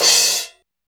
CYM 15 CHO0O.wav